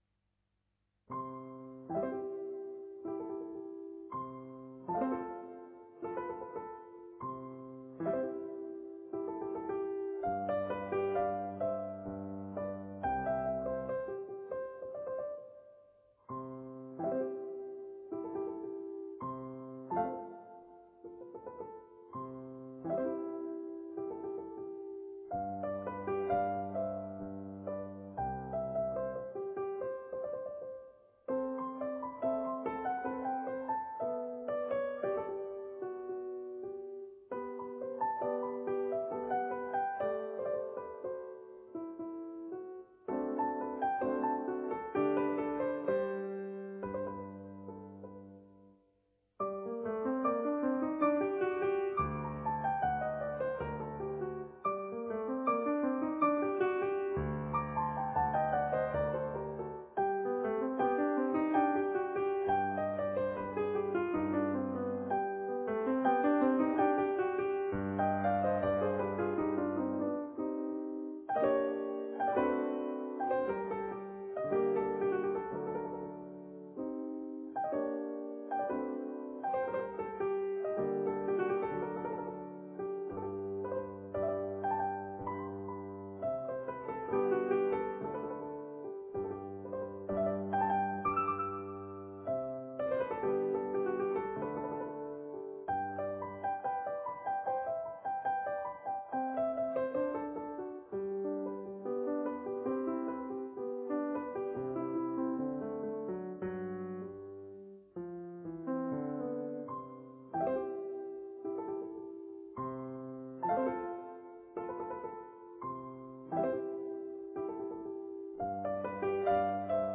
Aqui fica um cheirinho de Scarlatti, em piano: